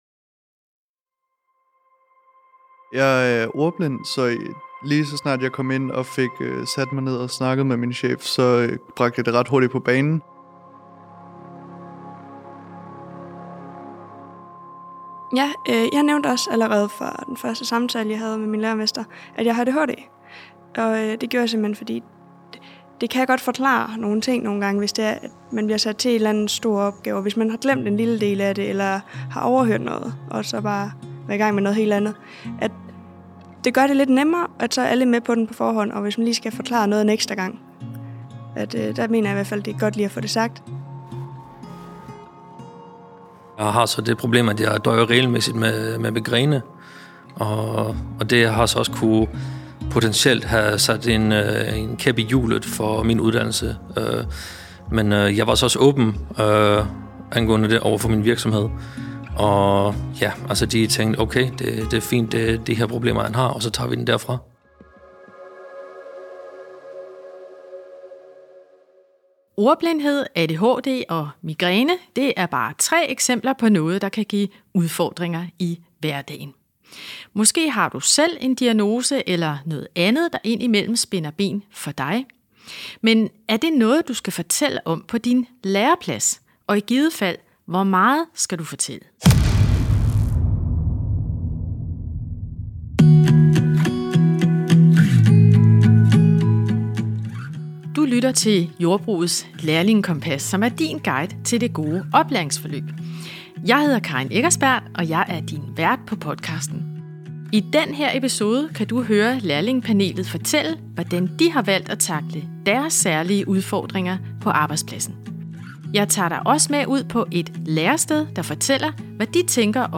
Du får erfaringer og gode råd fra et lærlingepanel, der deler deres oplevelser, og vi besøger en virksomhed for at høre, hvordan de ser på ansatte med særlige behov.